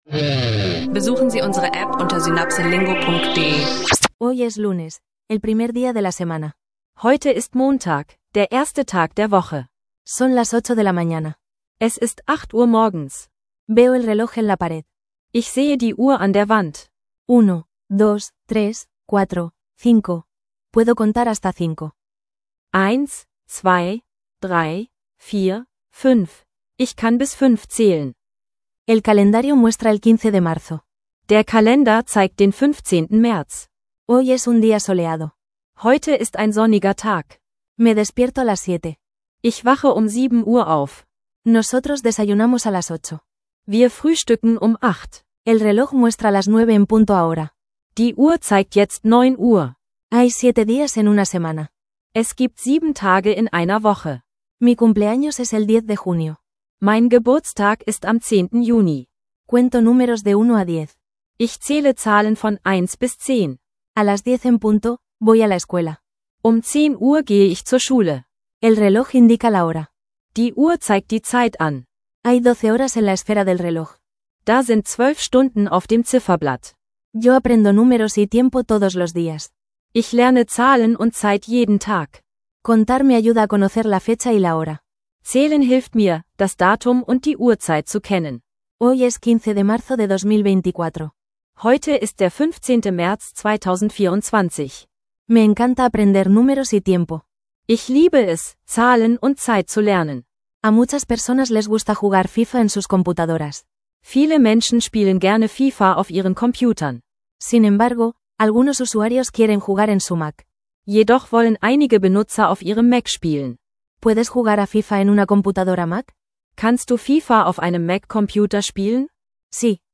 Erlebe eine zweisprachige Einführung zu Zahlen, Uhrzeit und Datum auf Spanisch, perfekt für Anfänger. In dieser Folge werden Grundzahlen, Zeiten und einfache Alltagsausdrücke geübt – ideal für Spanisch lernen online, Spanisch lernen Podcast und Spanisch für Anfänger.